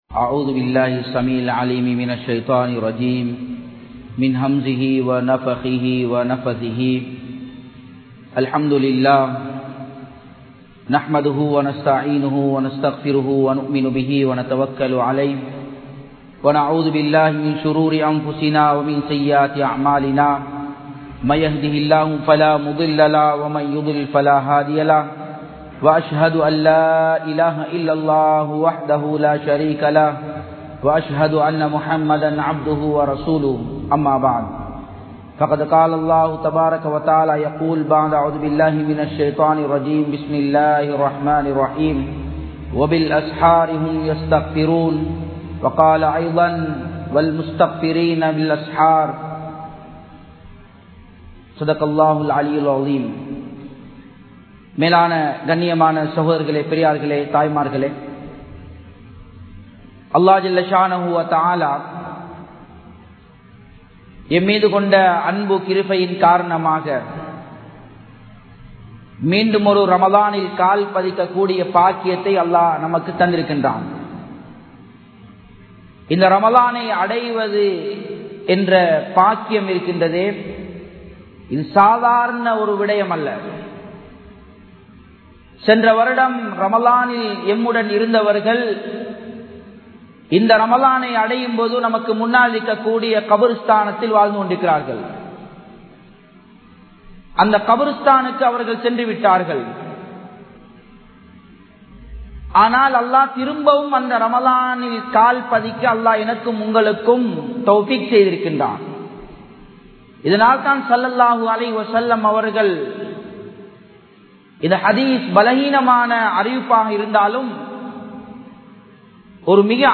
Ramalanum Samooha Ottrumaium (ரமழானும் சமூக ஒற்றுமையும்) | Audio Bayans | All Ceylon Muslim Youth Community | Addalaichenai